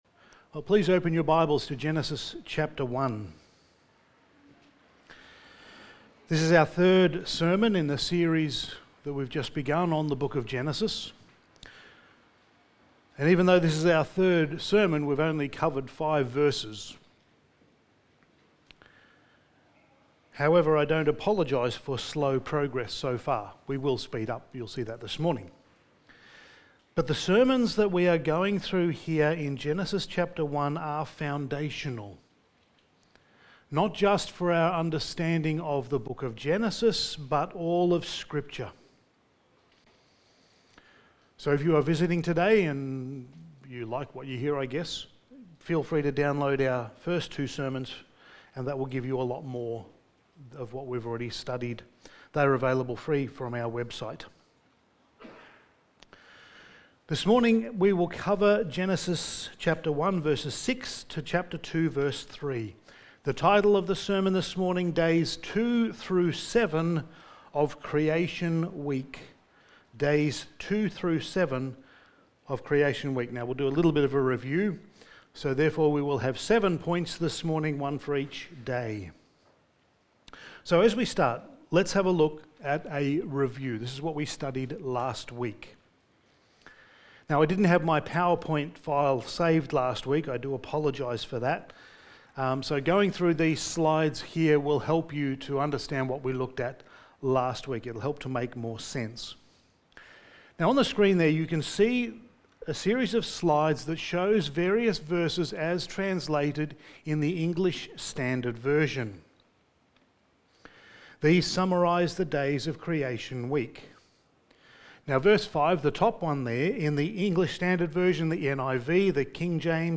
Sermon
Service Type: Sunday Morning